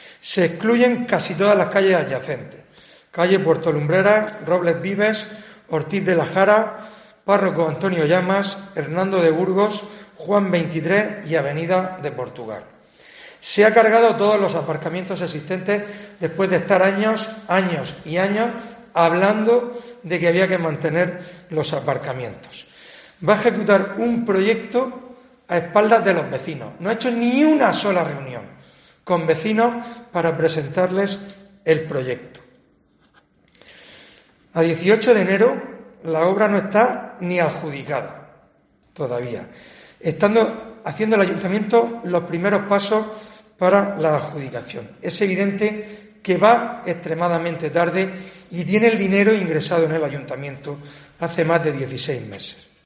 Fulgencio Gil, portavoz de PP